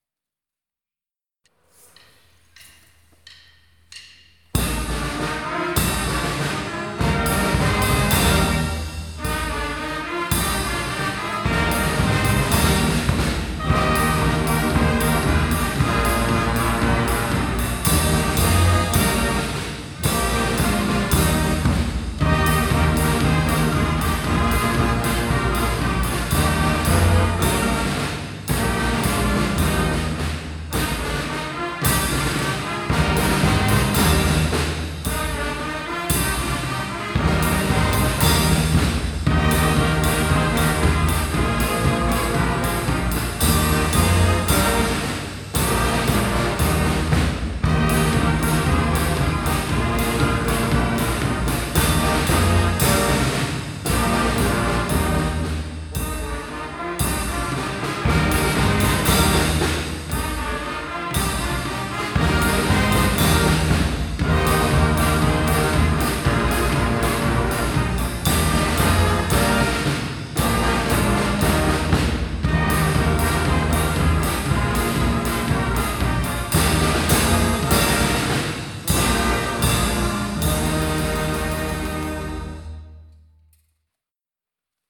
Orkiestra Dęta.